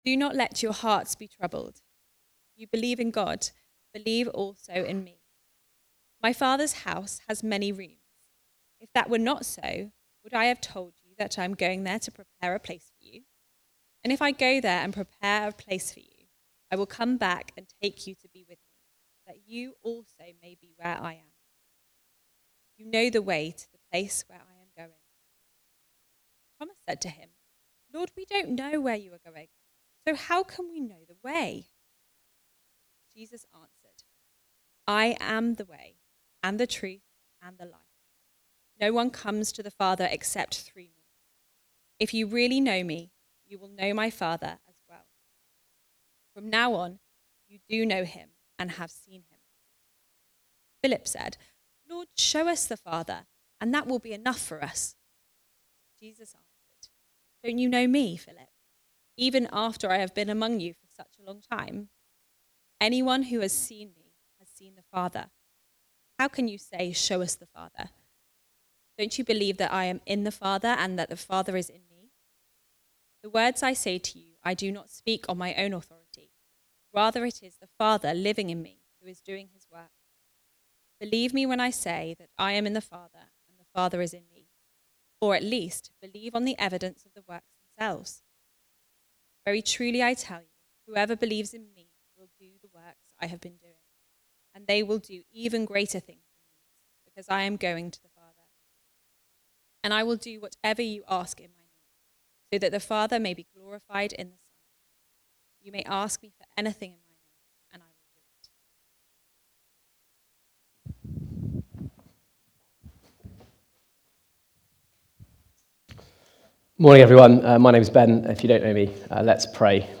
The Way (John 14:1-14) from the series Comfort and Joy. Recorded at Woodstock Road Baptist Church on 02 February 2025.